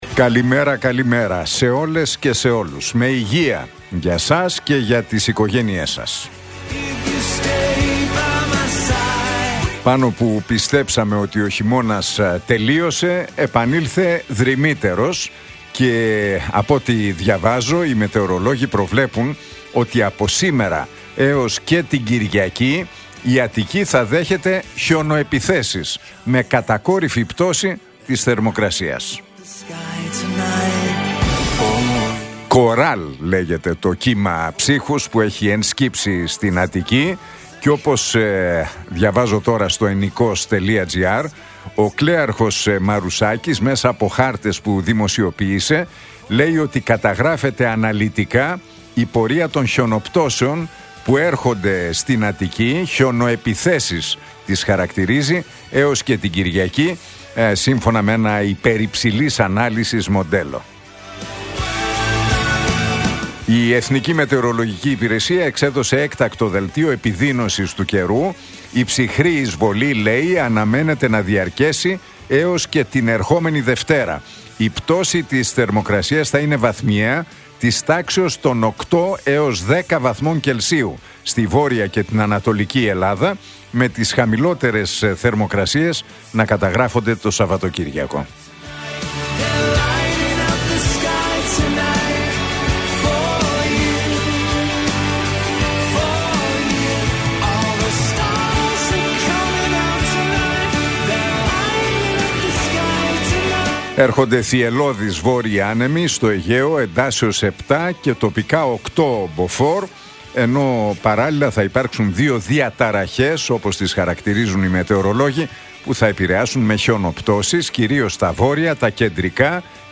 Ακούστε το σχόλιο του Νίκου Χατζηνικολάου στον ραδιοφωνικό σταθμό RealFm 97,8, την Τετάρτη 19 Φεβρουαρίου 2025.